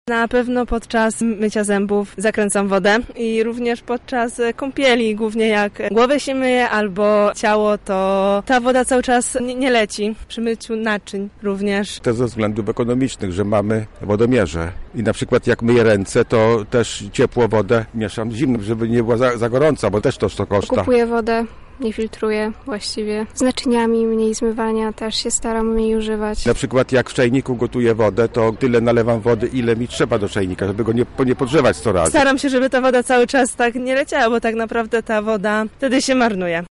Czy mieszkańcy Lublina oszczędzają wodę? O to zapytała nasza reporterka:
mieszkańcy Lublina